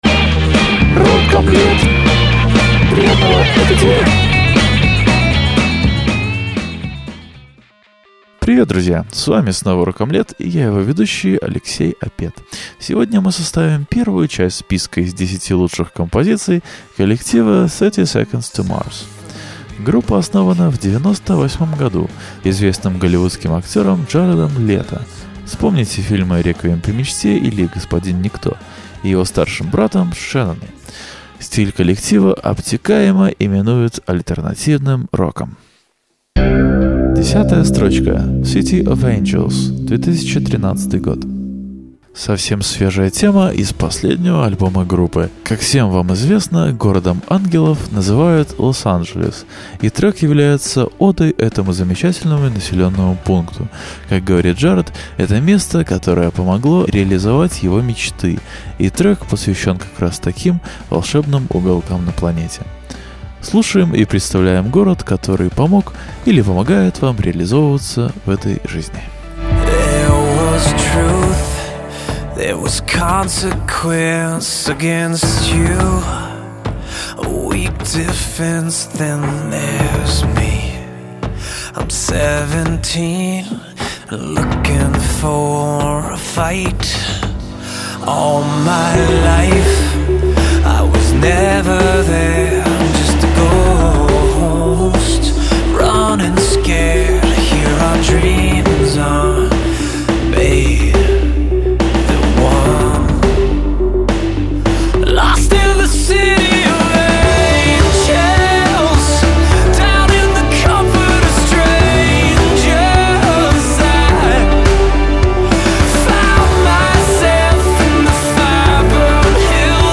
Стиль коллектива обтекаемо именуют альтернативным роком.